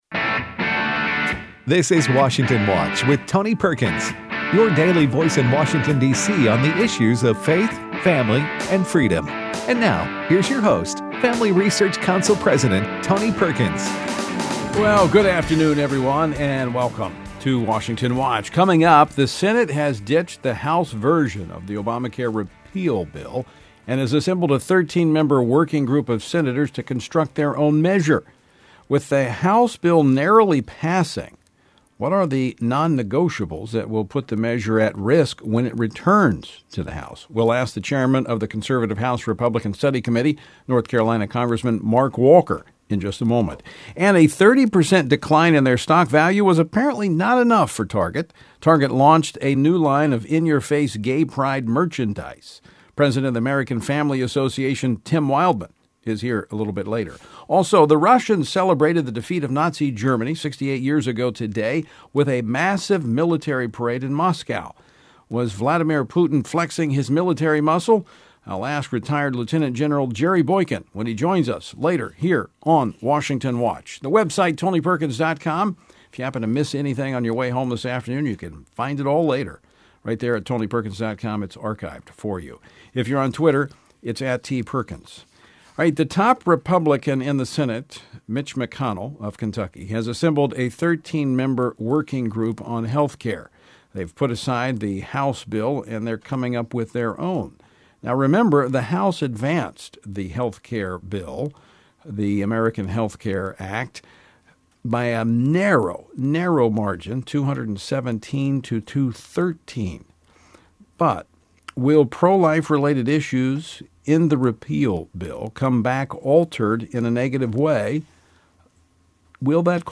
Tony Perkins talk with Rep. Mark Walker in the first segment of the show concerning repeal/Planned Parenthood in the Senate.
Finally, in the third segment, Tony talk with Gen. Jerry Boykin on Russian military display and increase of US Troops in Afghanistan.